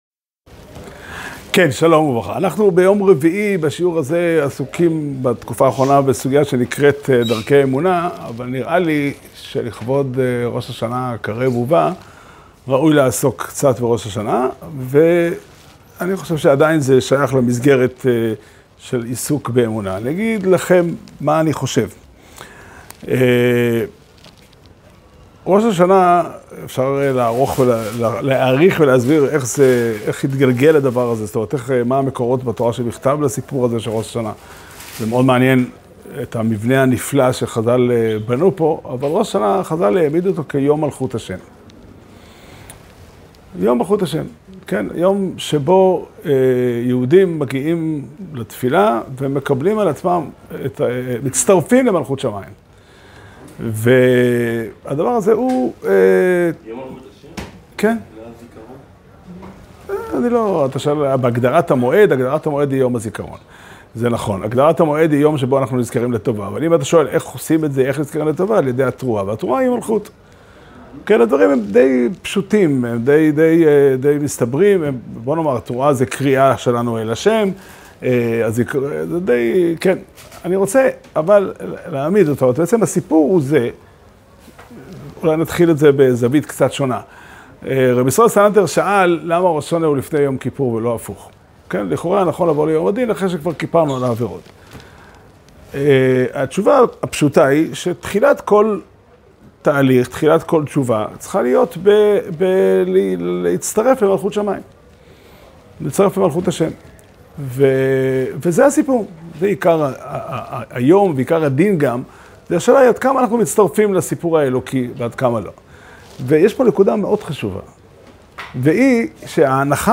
שיעור שנמסר בבית המדרש פתחי עולם בתאריך כ"א אלול תשפ"ד